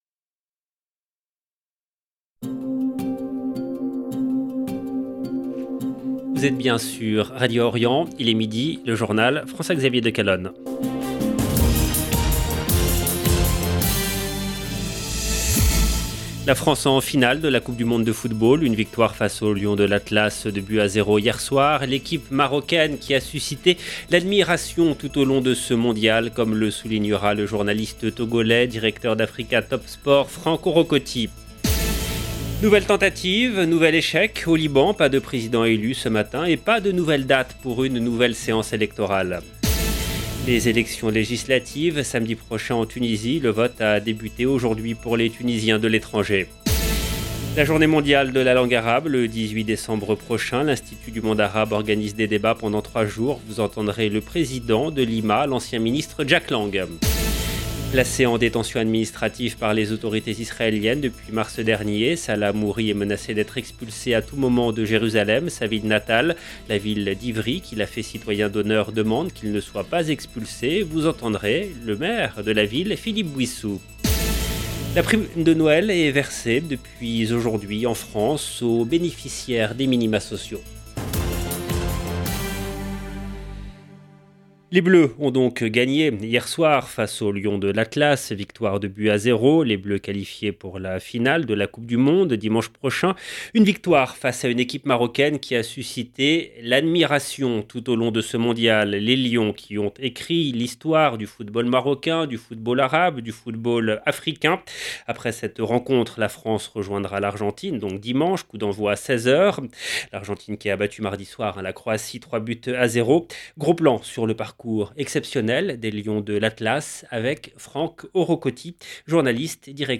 EDITION DU JOURNAL DE 12 H EN LANGUE FRANCAISE DU 15/12/2022
Vous entendrez le président de l'IMA, Jack Lang.
Vous entendez le maire de la ville, Philippe Bouyssou.